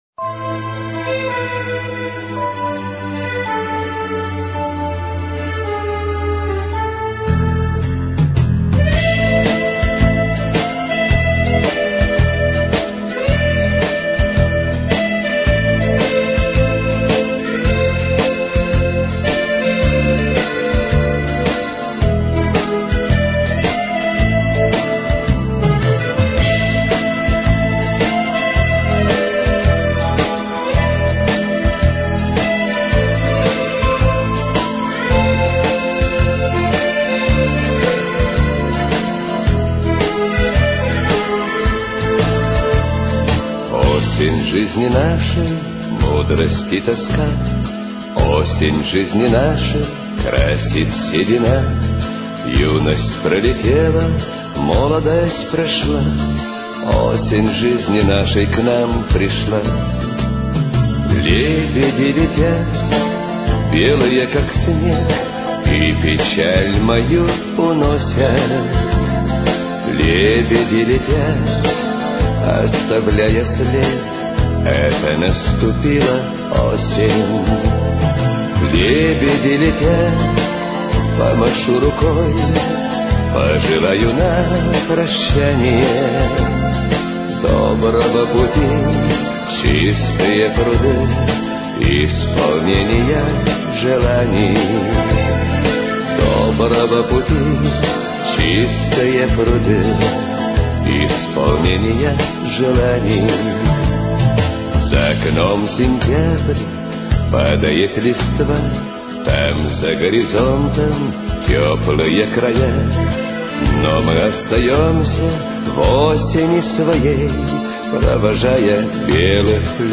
Концерт ко Дню пожилого человека
Праздничная программа ко Дню пожилого человека.